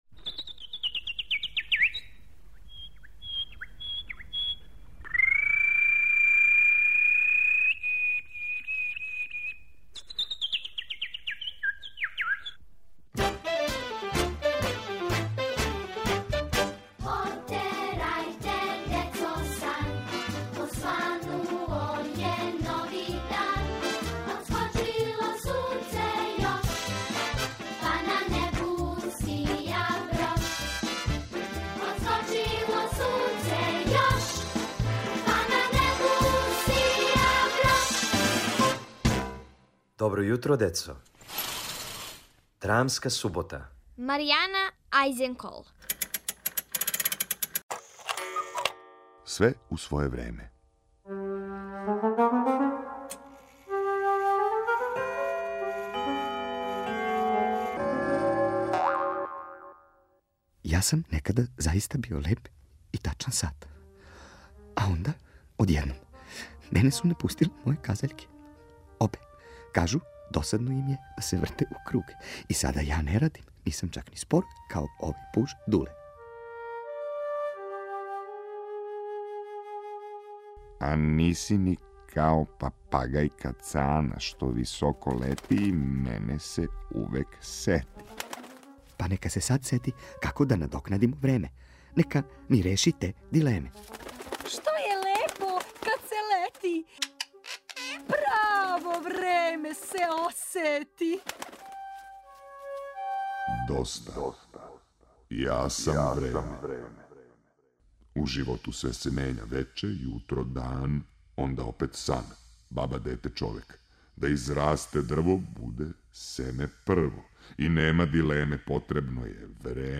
Слушате трећи део мале радио драме "Све у своје време" у којој су казаљке одлучиле да напусте сат. Шта ће сада сат?